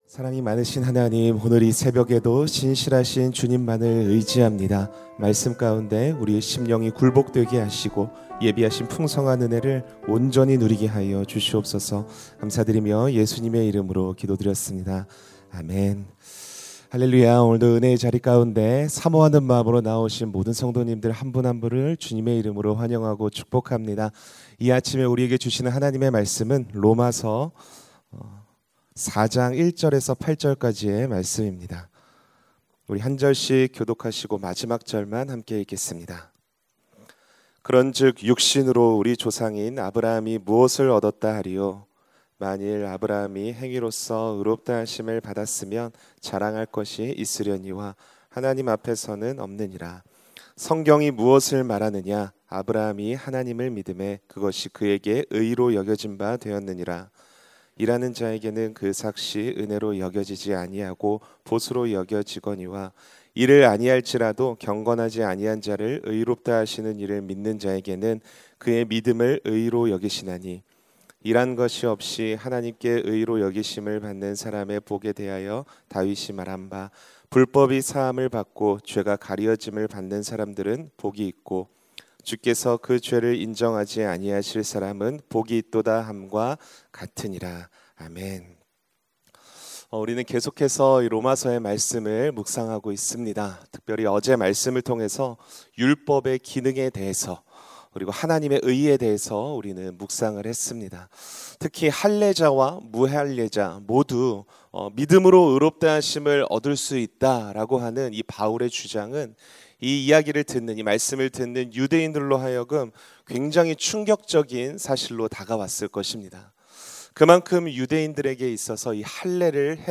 [새벽예배]